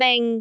speech
syllable
pronunciation
bing3.wav